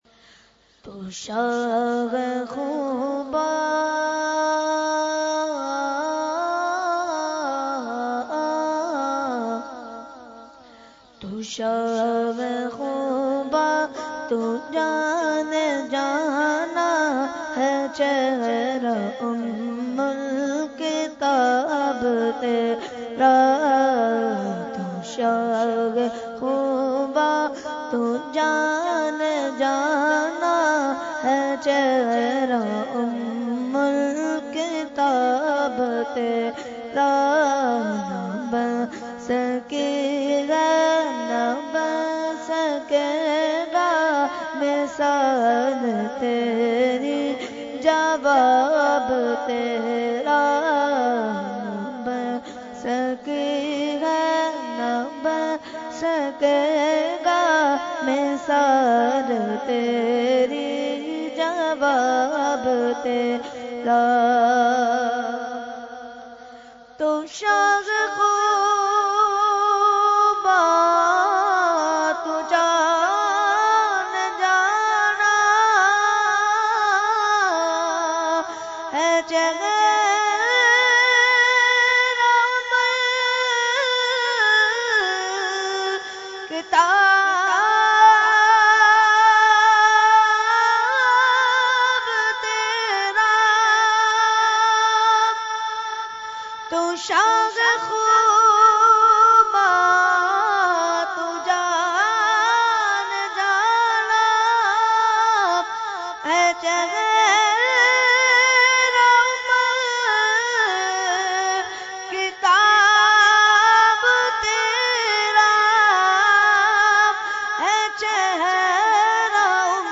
Category : Naat | Language : UrduEvent : Urs Qutbe Rabbani 2018